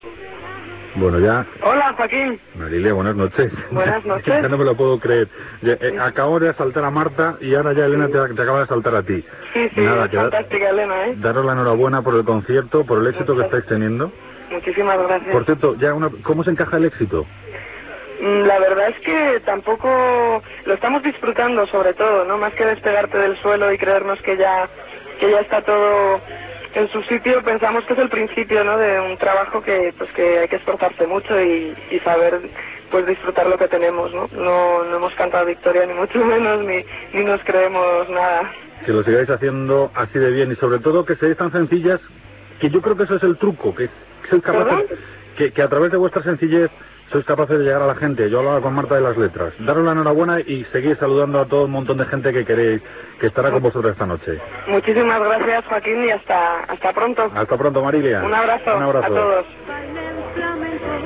Entrevista a Marilia, La Gramola